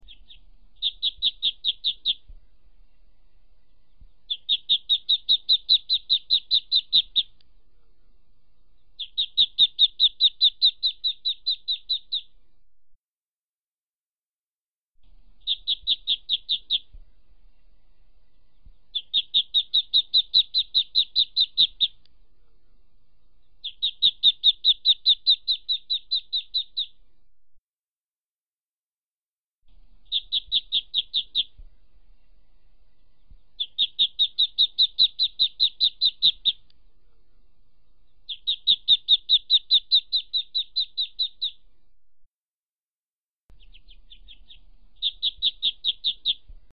Tiếng Vịt con kêu mp3